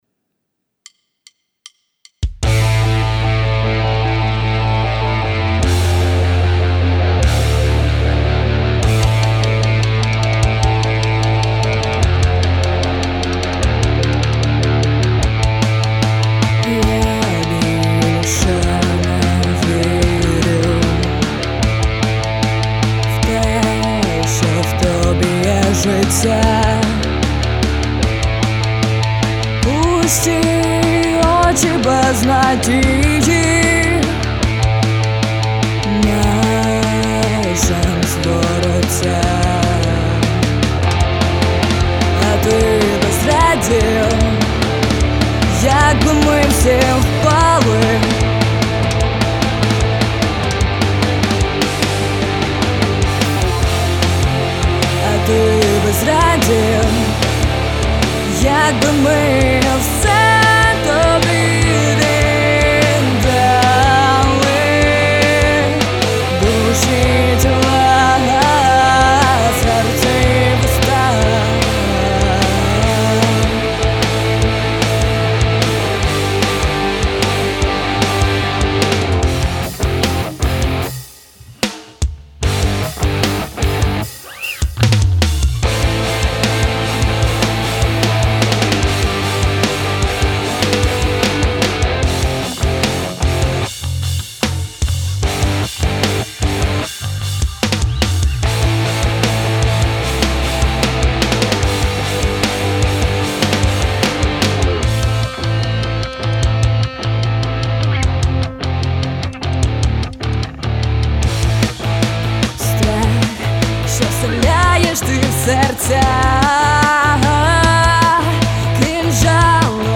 Слухайте, якщо Вам близька рок-музика)